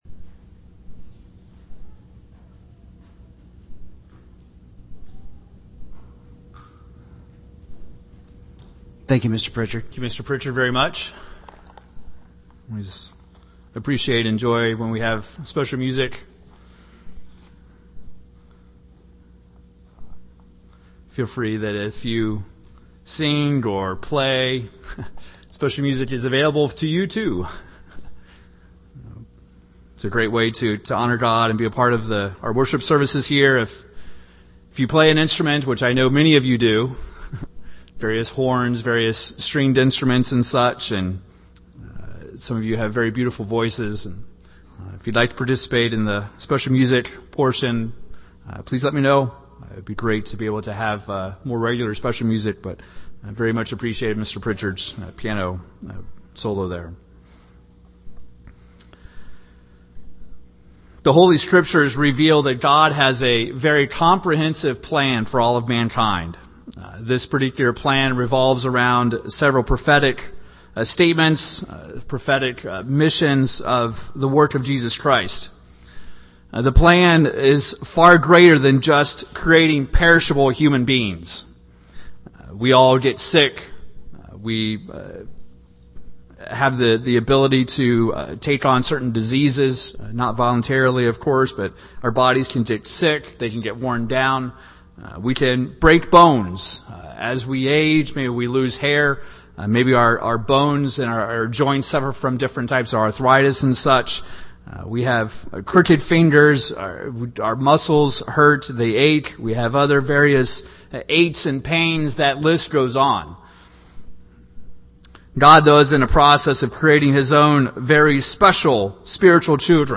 We have been created physical, but our destiny, purpose and future was not designed for us to remain that way. In this sermon we'll review briefly God’s Plan of Salvation, and the purpose of our physical existence.